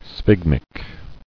[sphyg·mic]